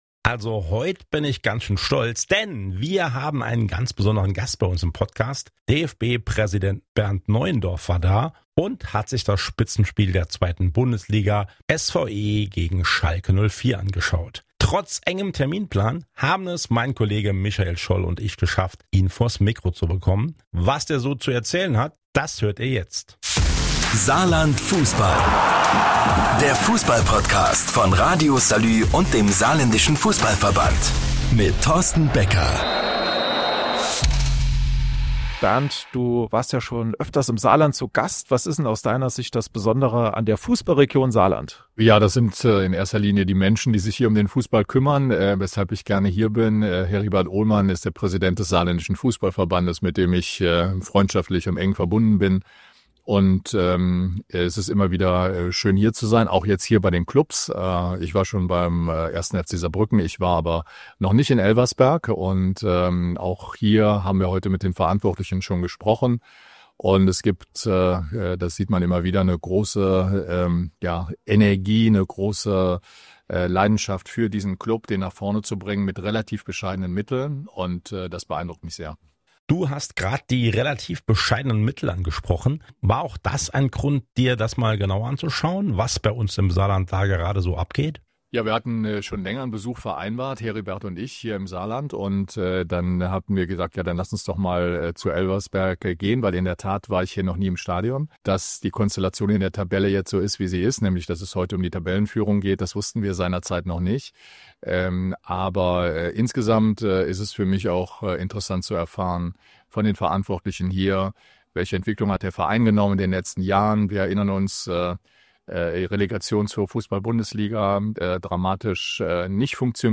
Beschreibung vor 4 Tagen Es war das Spitzenspiel: SV Elversberg gegen FC Schalke 04 Und mittendrin: Bernd Neuendorf. Wir haben den DFB-Präsidenten kurz vors Mikro bekommen – sprechen über die Aufstiegschancen der SVE, die Bedeutung des Fußballs im Saarland und lernen ihn auch mal ganz privat kennen.